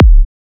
edm-kick-14.wav